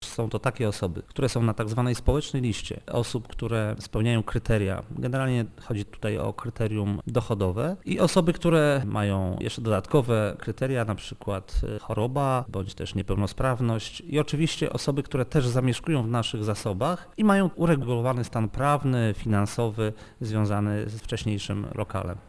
Kto konkretnie zamieszka w nowych lokalach jeszcze nie wiadomo, ale chętnych jest wielu - mówi burmistrz Włodawy Jerzy Wrzesień: